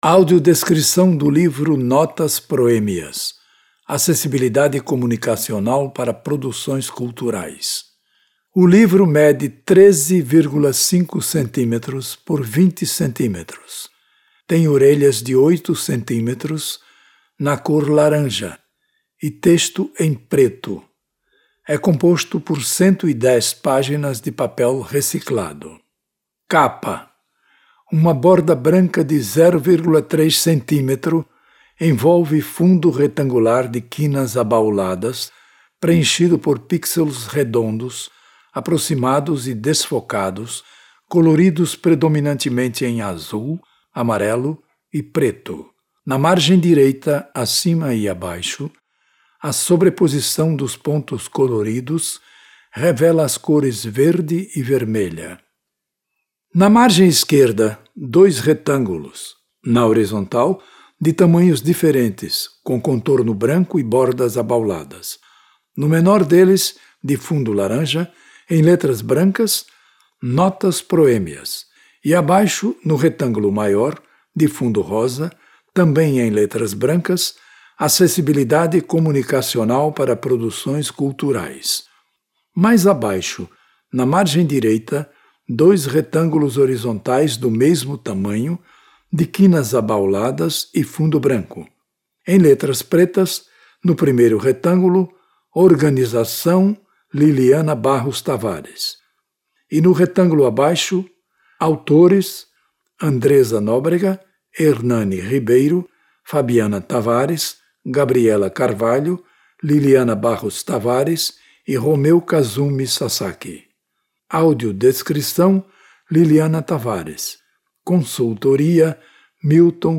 AUDIODESCRI��O LIVRO